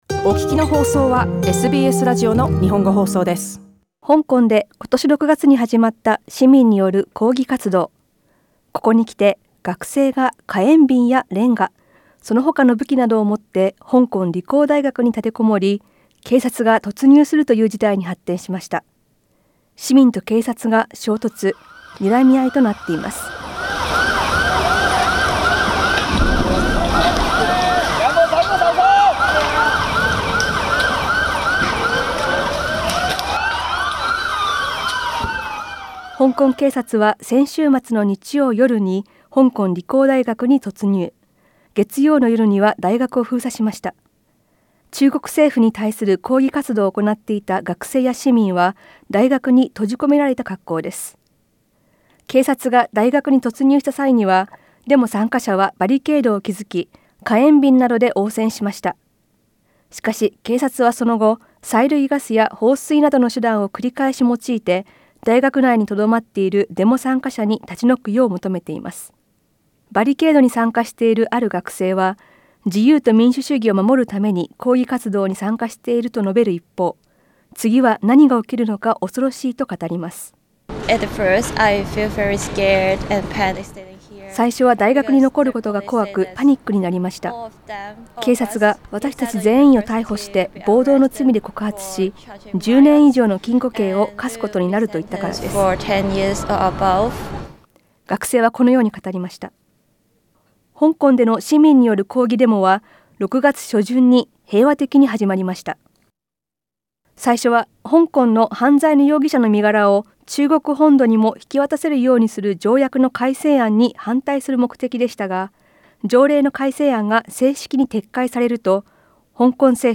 香港での市民と警察の衝突、今後の展望について、詳しくは音声リポートをどうぞ 。